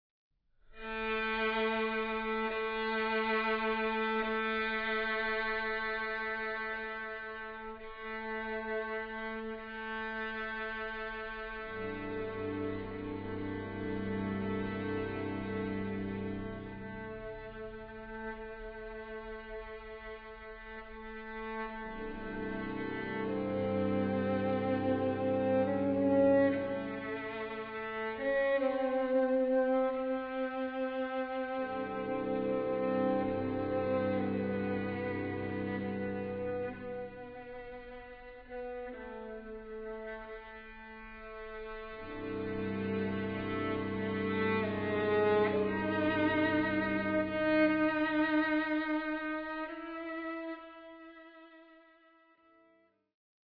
with Bass Drum